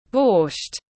Súp củ cải đỏ tiếng anh gọi là borscht, phiên âm tiếng anh đọc là /bɔːʃt/
Borscht /bɔːʃt/